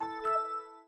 Windows Logon Sound Melody nWindows Startup Audio Tone nSystem Login Sound Effect nWindows Welcome Sound Clip nPC Logon Audio Signature